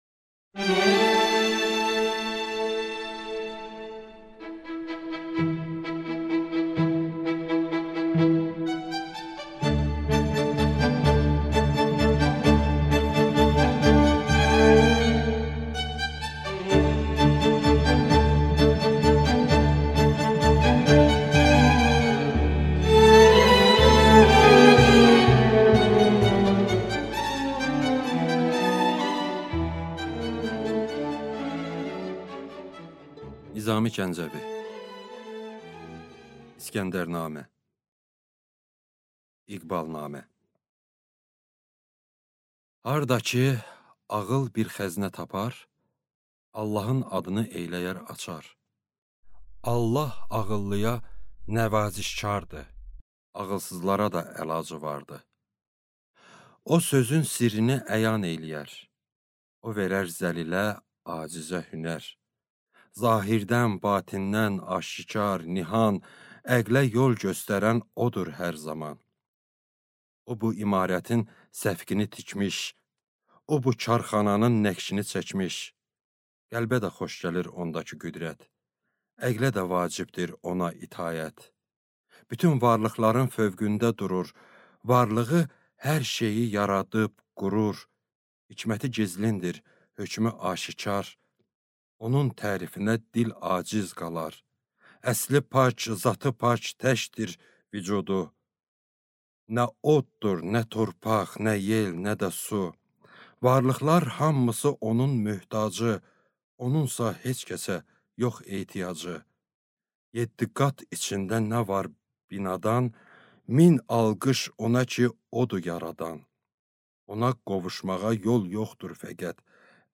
Аудиокнига İsgəndərnamə (İqbalnamə) | Библиотека аудиокниг